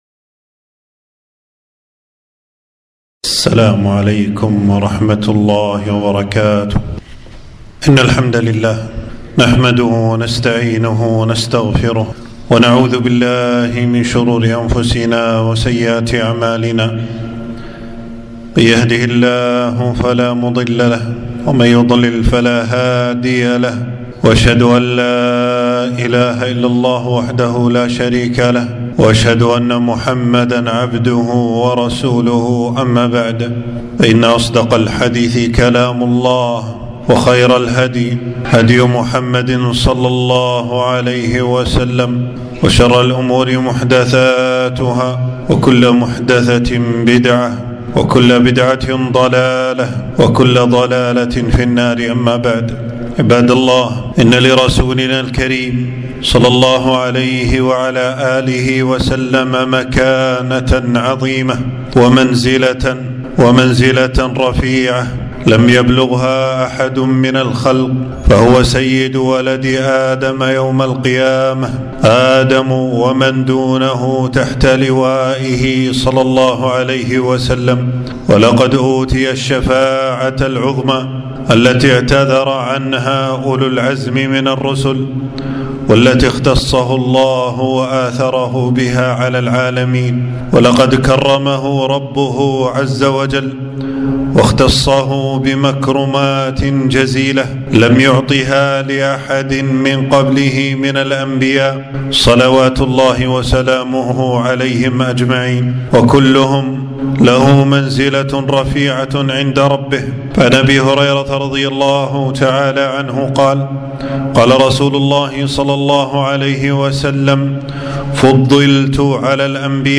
خطبة - حقوق النبي ﷺ ووجوب الأخذ بسنته